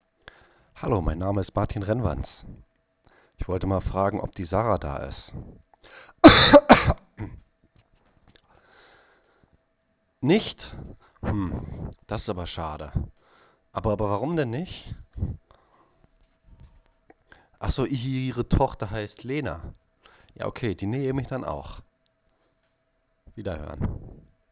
husten.wav